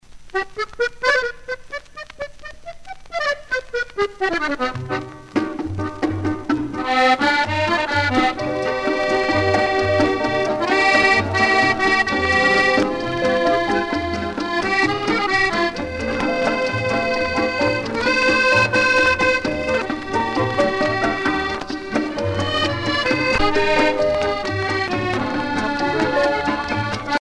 danse : boléro
Pièce musicale éditée